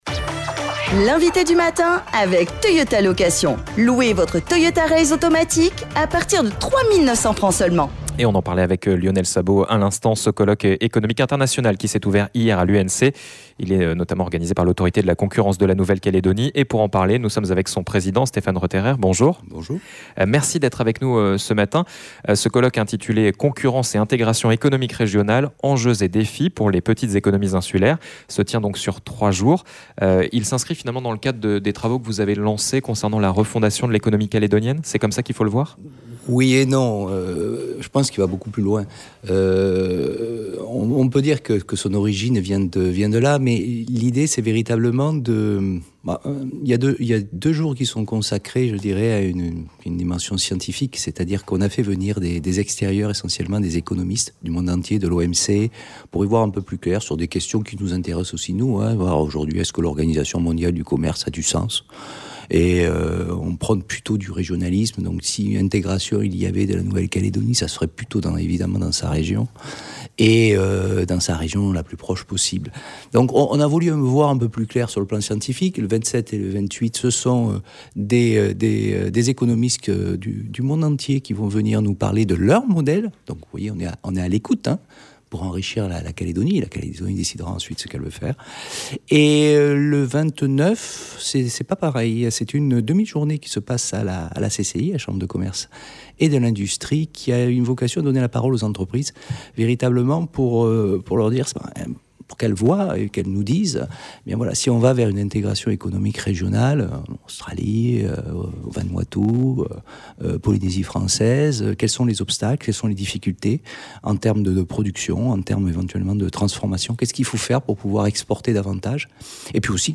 Nous reviendrons sur ce rendez-vous avec Stéphane Retterer, le président de l’autorité de la concurrence de Nouvelle-Calédonie.